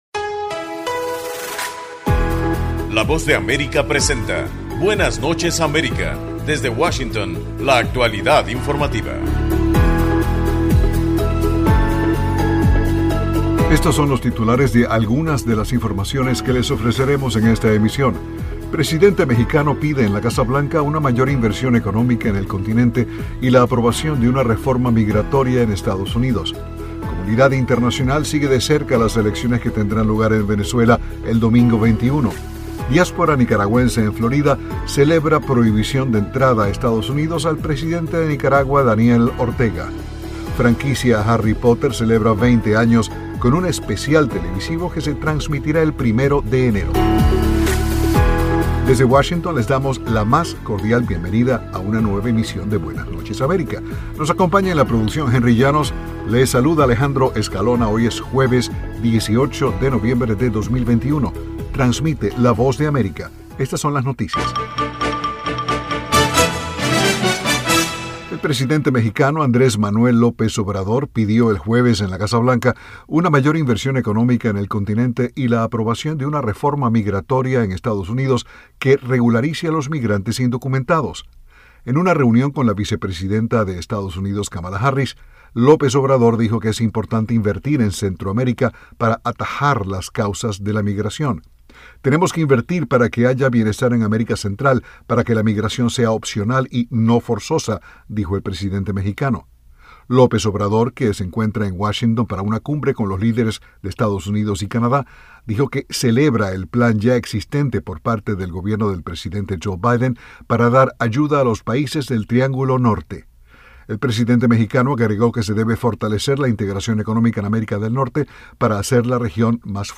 Programa informativo de la Voz de América, Buenas Noches América.